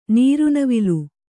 nīru navilu